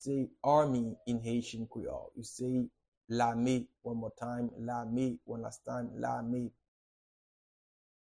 Pronunciation and Transcript:
How-to-say-Army-in-Haitian-Creole-Lame-pronunciation.mp3